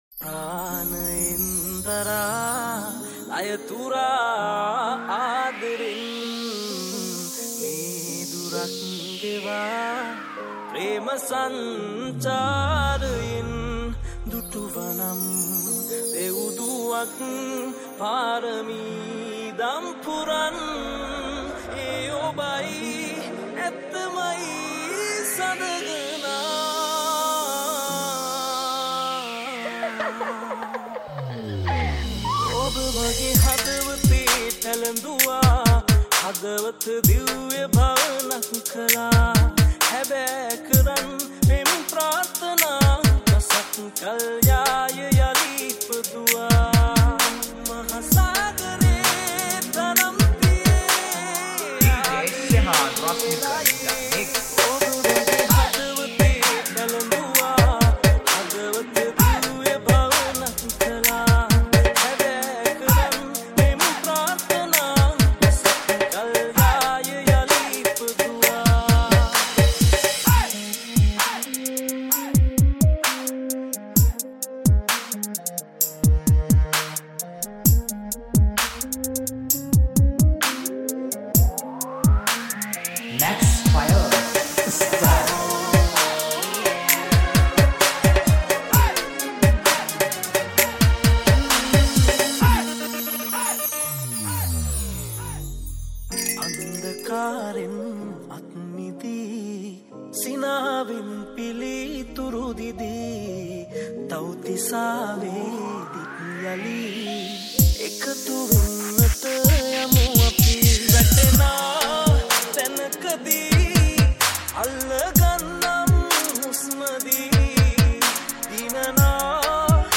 Hip Hop Mix